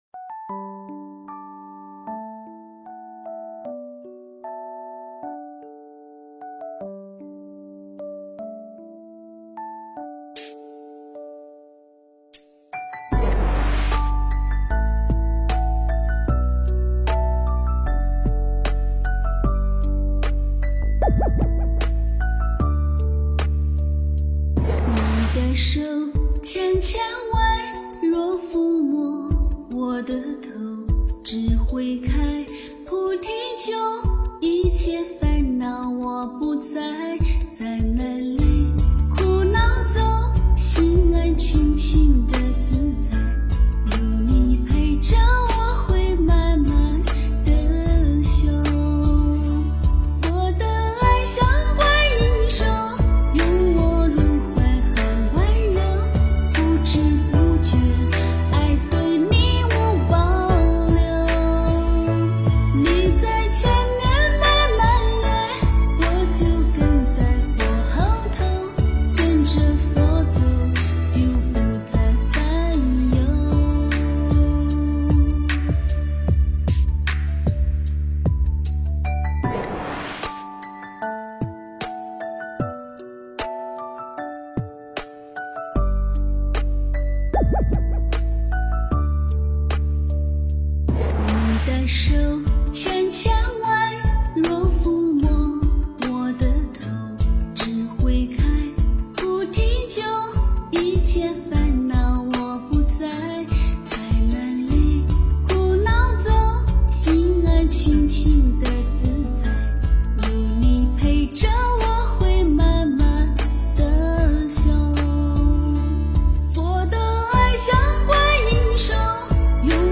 观音手 - 诵经 - 云佛论坛